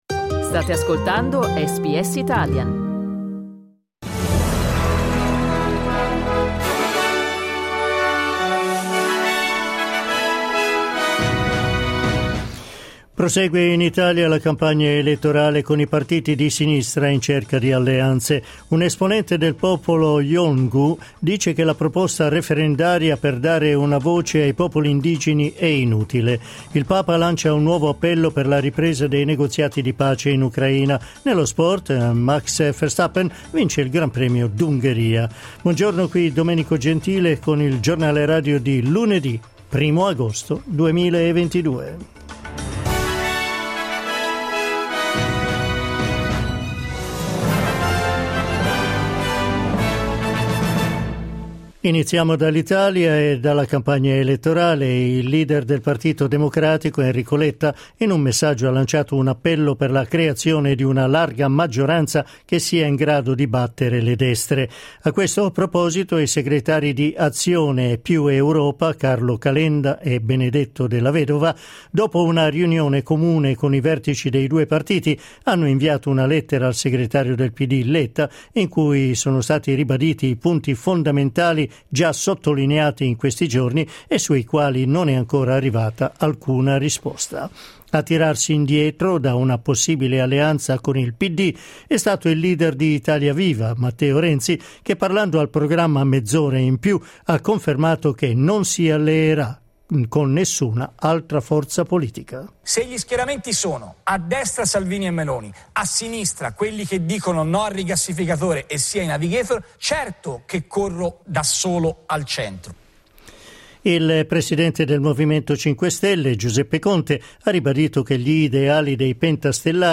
Giornale radio lunedì 1 agosto 2022
Il notiziario di SBS in italiano.